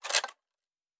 Metal Tools 06.wav